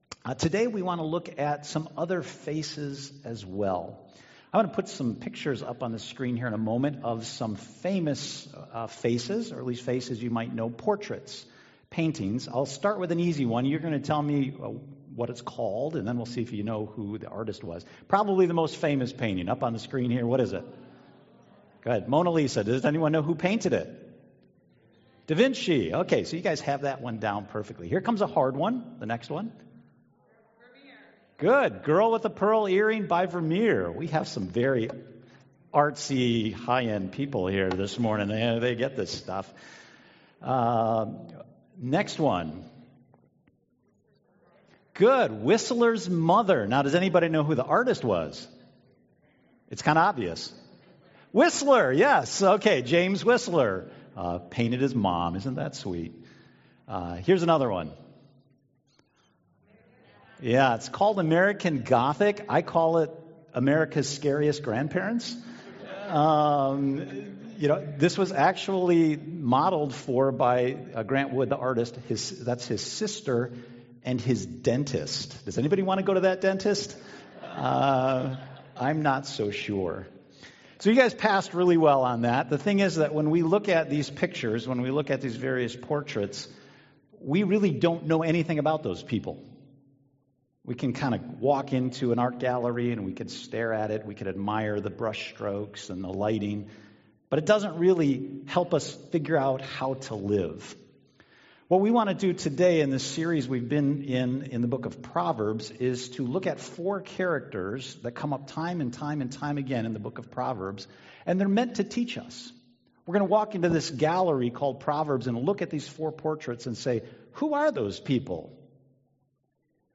Acting on God's Promises Service Type: Sunday Morning « Rule Your City Renewing and Pursuing